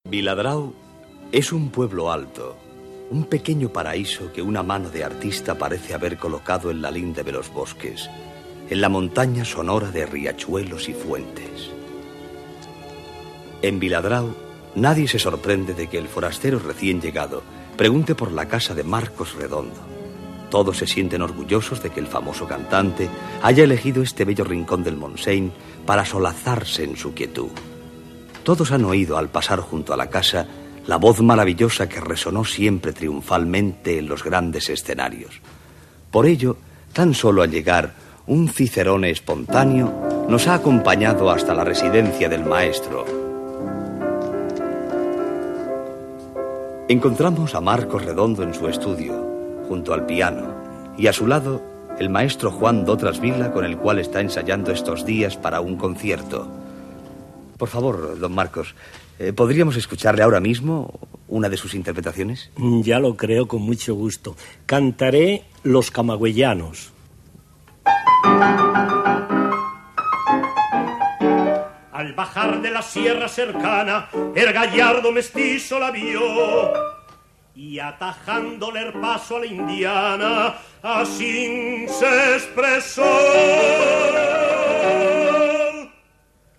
Semblança de Marcos Redondo, al poble de Viladrau. Redondo canta una romança.
Cultura